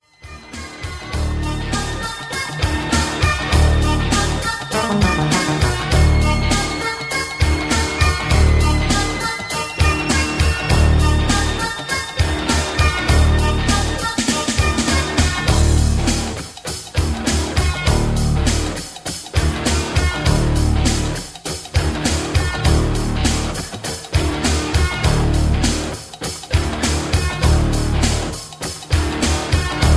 Key-F) Karaoke MP3 Backing Tracks
Just Plain & Simply "GREAT MUSIC" (No Lyrics).